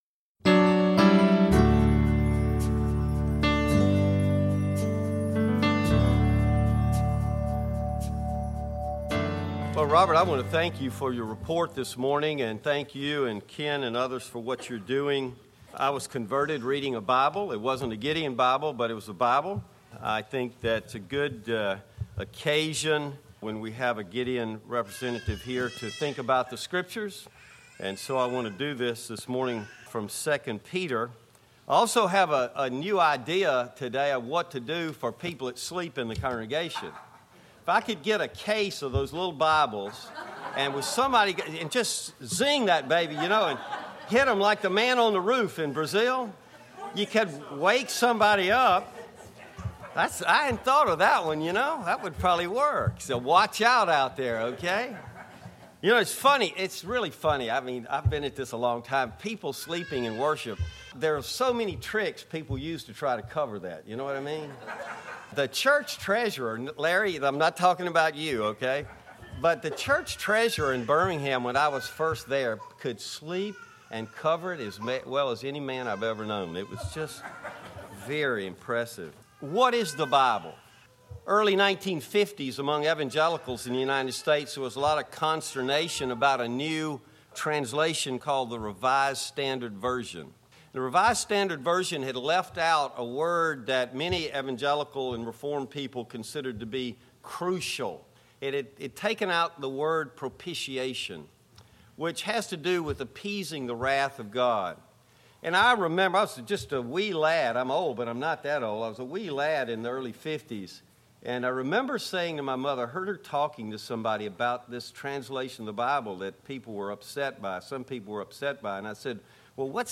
Bible Text: 2 Peter 1:16-21 | Preacher